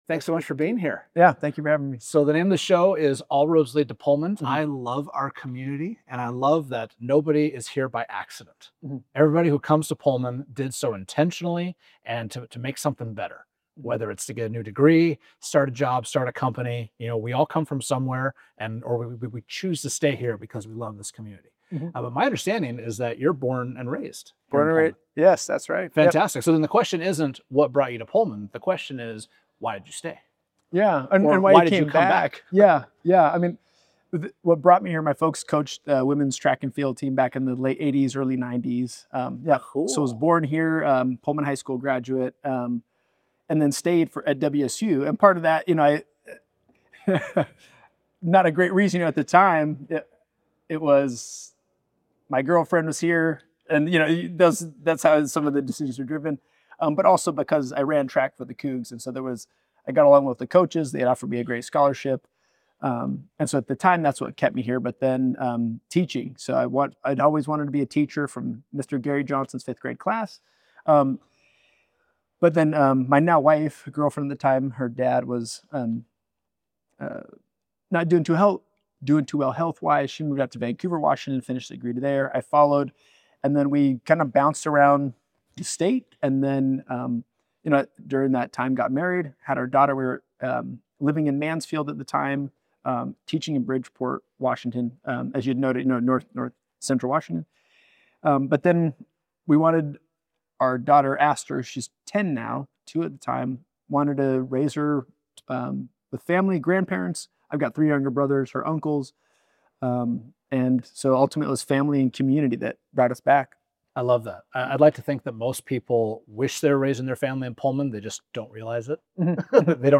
We sat down for a lively conversation covering real estate, the health of our aquifer, city staffing concerns, and even the debate over fireworks.